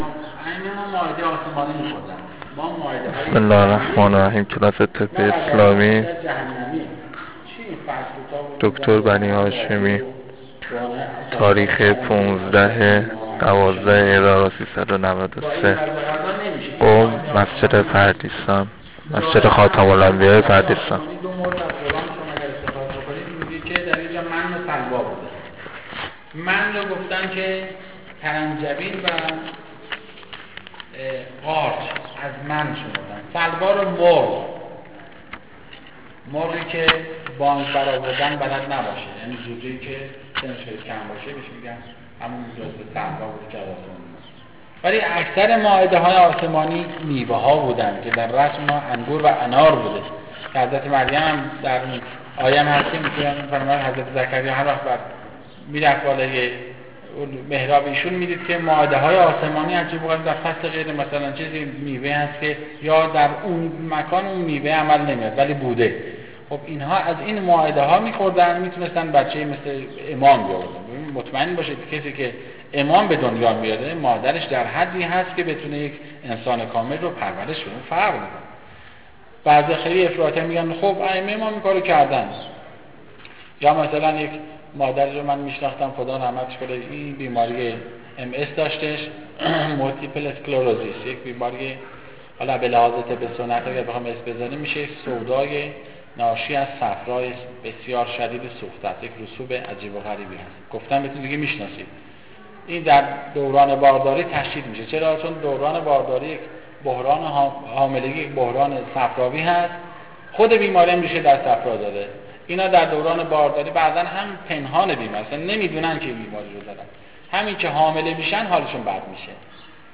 قم مسجد خاتم الانبیاء پردیسان